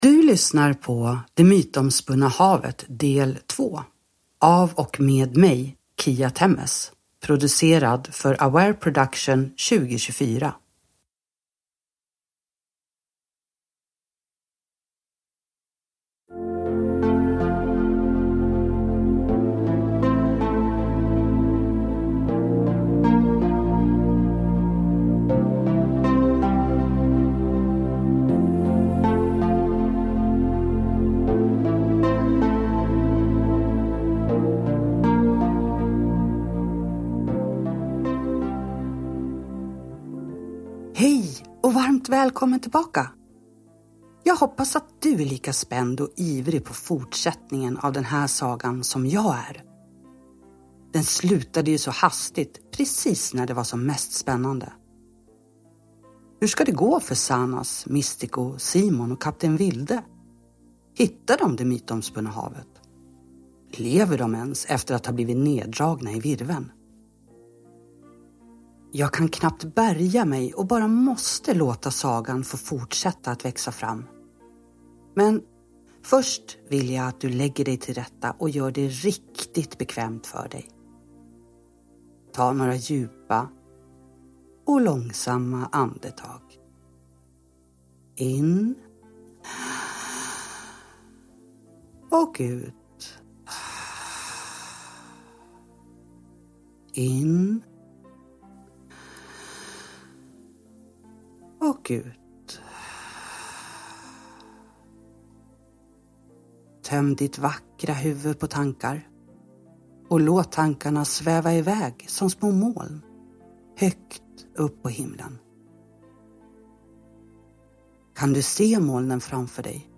Det mytomspunna havet del 2, en guidad godnattsaga – Ljudbok